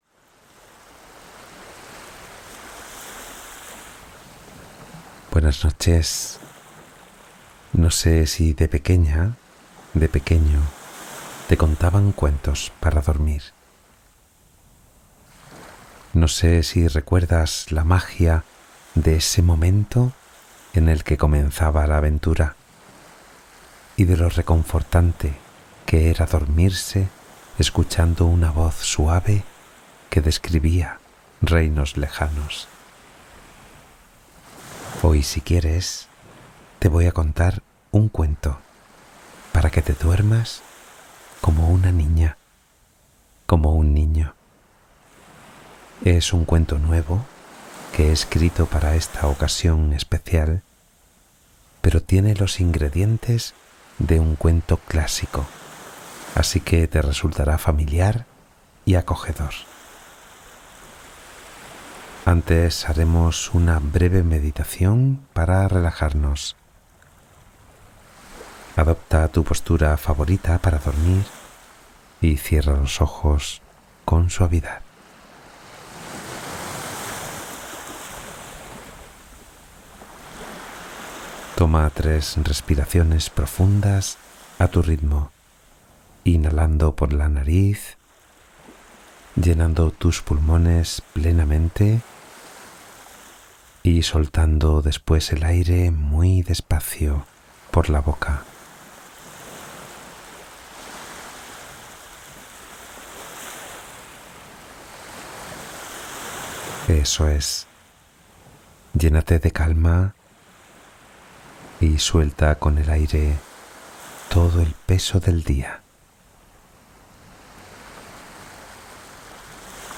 Duerme fácilmente con esta meditación narrativa guiada para descansar